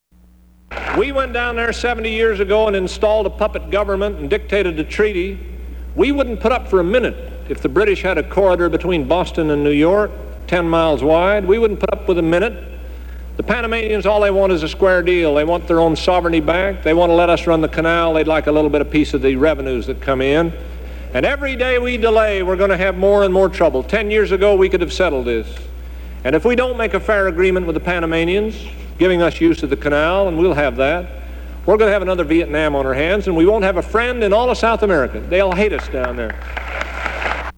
U.S. Congressman Morris Udall responds to a question about the Panama Canal
Morris Udall responds to a question about the Panama Canal in a campaign appearance at Michigan State University, speaking in favor of returning control of the canal to Panama.